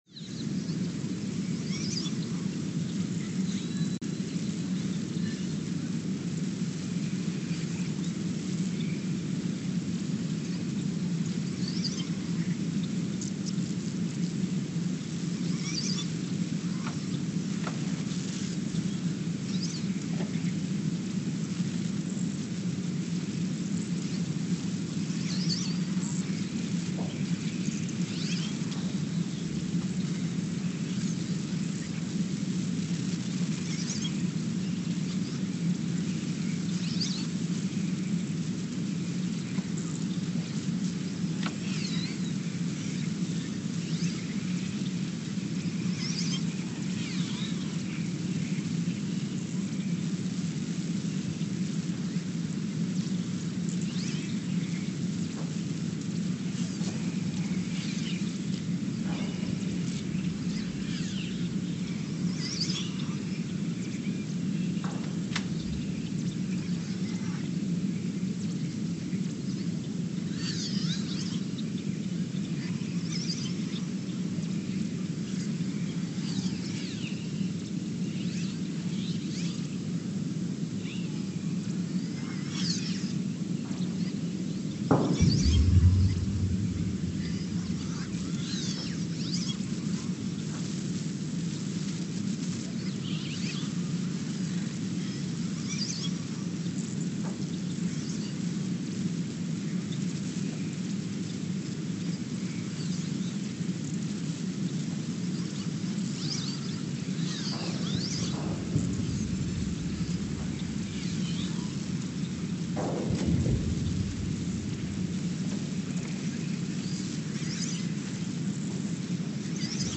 Ulaanbaatar, Mongolia (seismic) archived on February 7, 2024
No events.
Sensor : STS-1V/VBB
Speedup : ×900 (transposed up about 10 octaves)
Loop duration (audio) : 03:12 (stereo)
SoX post-processing : highpass -2 90 highpass -2 90